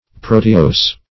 Search Result for " proteose" : The Collaborative International Dictionary of English v.0.48: Proteose \Pro"te*ose`\, n. [Proteid + -ose.]